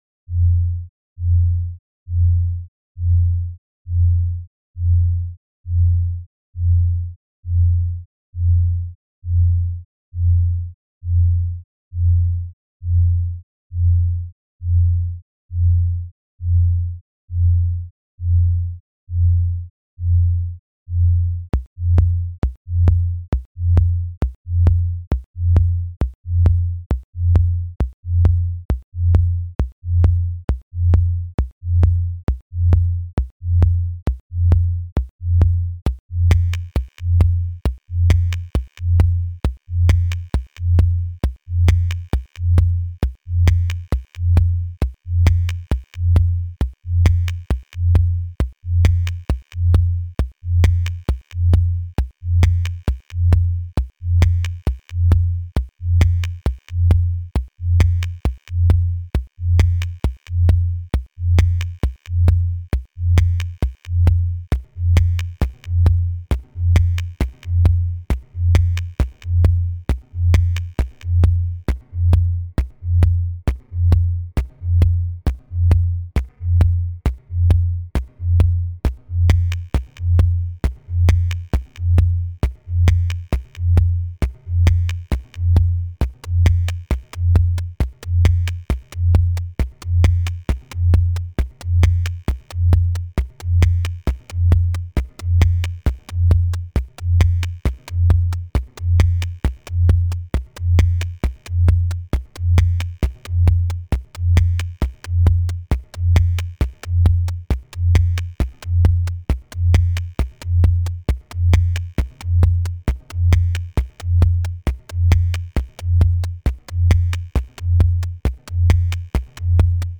minimal experimental